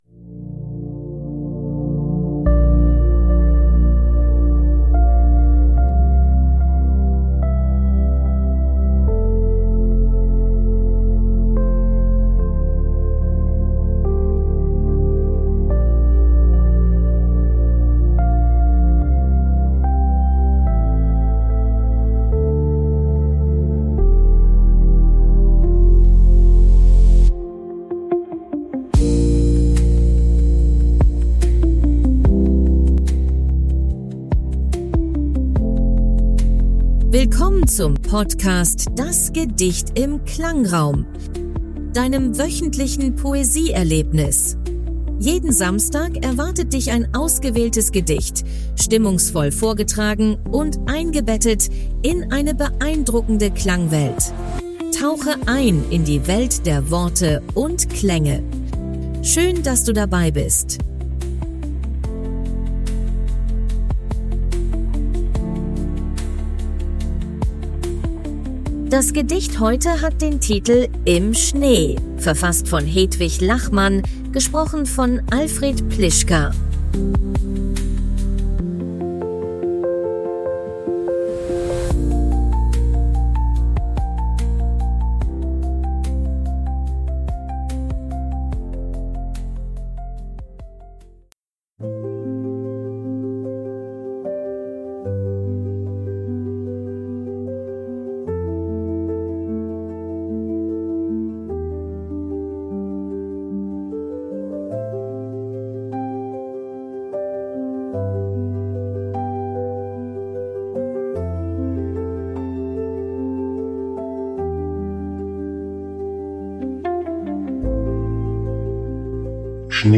Klangraummusik: KI generiert.